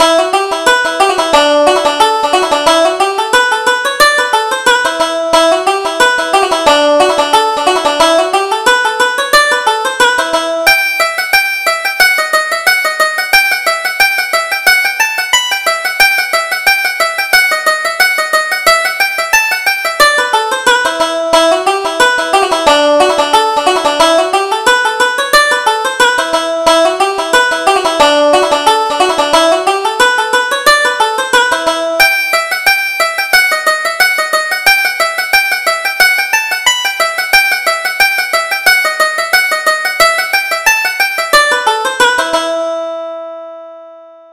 Reel: The Templehouse